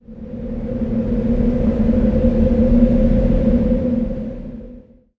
sounds / ambient / cave
cave7.ogg